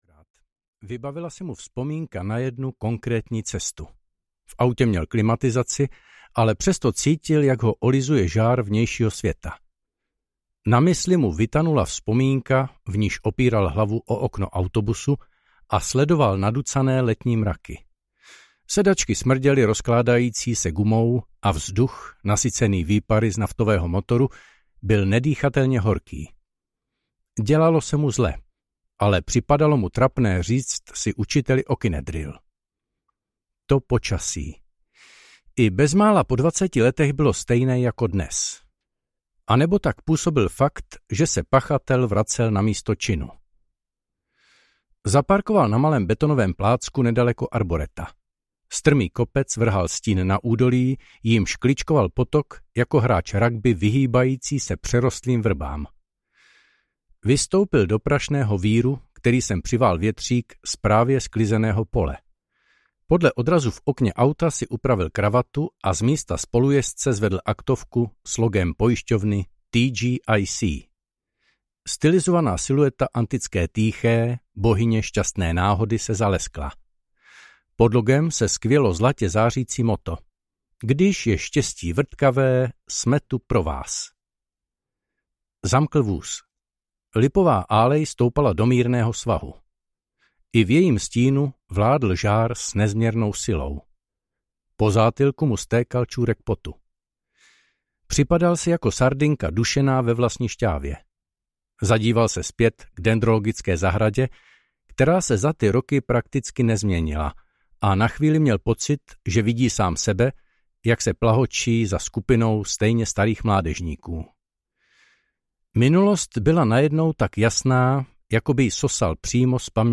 Ozvěny záhrobí audiokniha
Ukázka z knihy